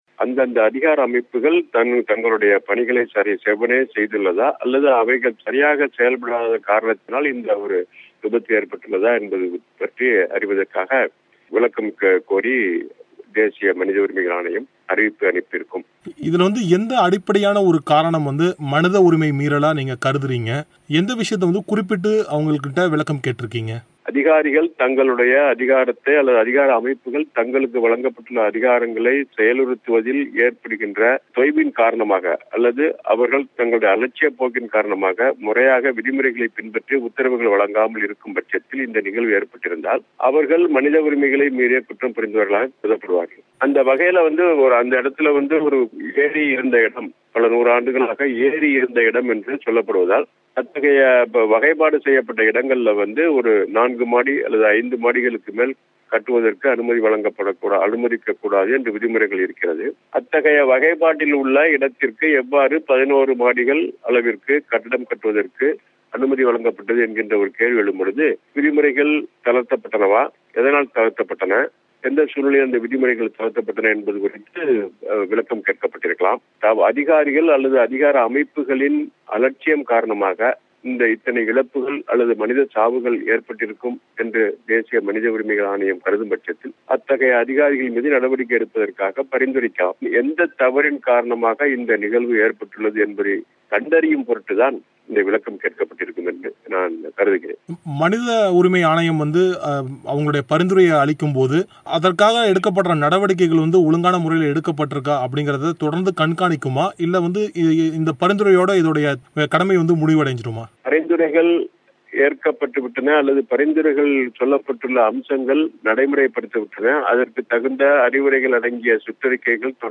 இது குறித்து தமிழ்மாநில மனித உரிமை ஆணைய உறுப்பினர் கே.பாஸ்கரன் பிபிசி தமிழோசைக்கு வழங்கிய பிரத்யேக செவ்வி Share Facebook X Subscribe Next சென்னை கட்டிட விபத்து: விதிமுறைகள் பின்பற்றப்படுகின்றானவா?